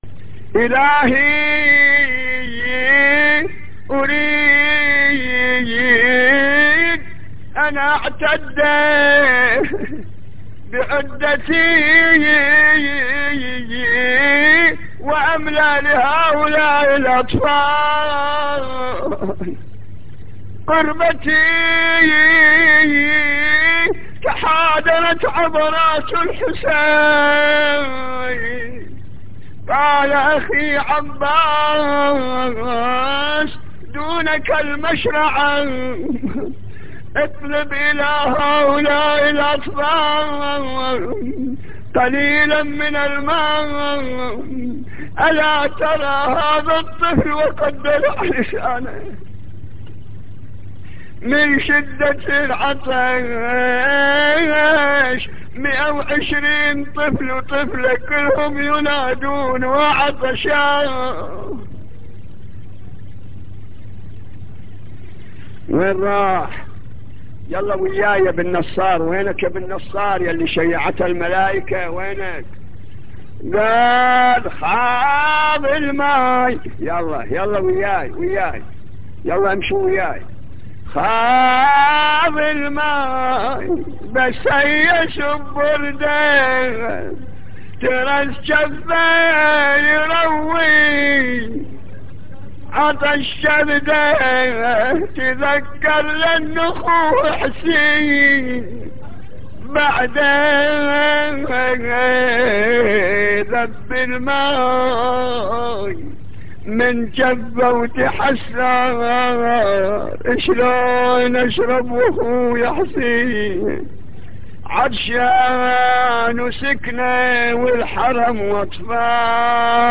نواعي حسينية8